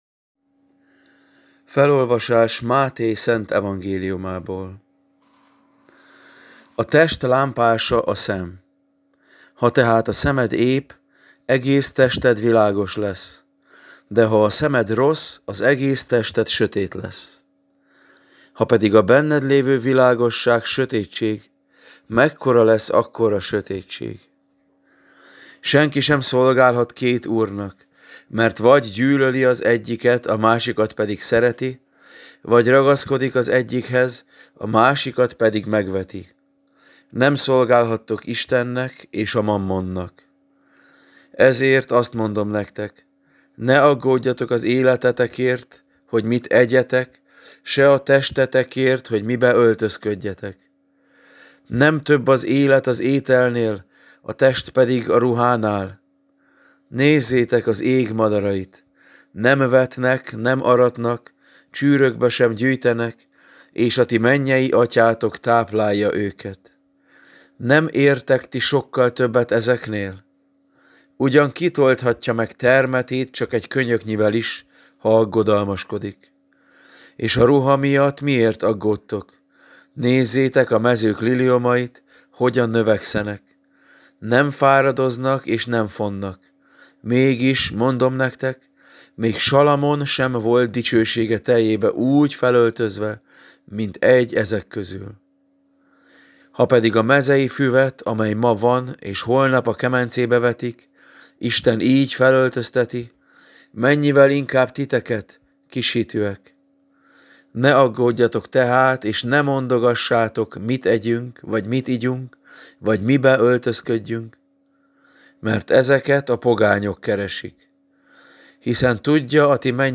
Evangéliumi olvasmány (Mt 6,22-33)